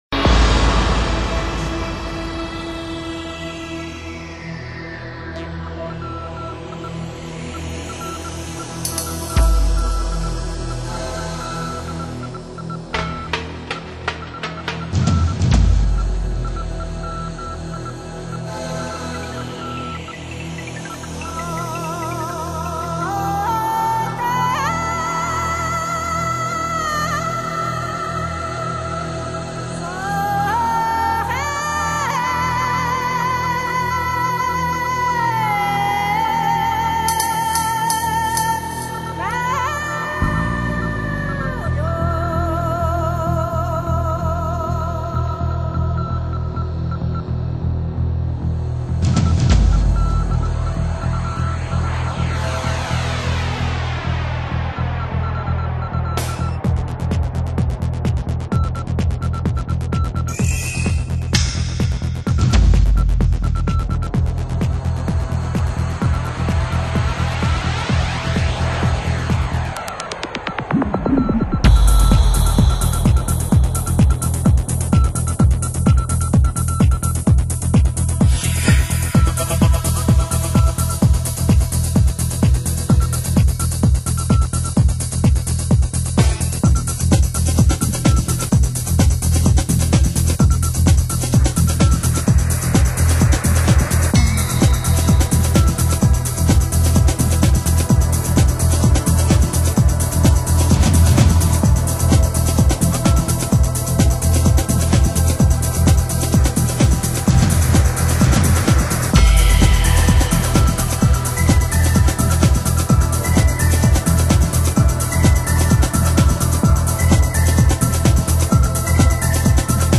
风格:发烧音乐 新音乐 迷幻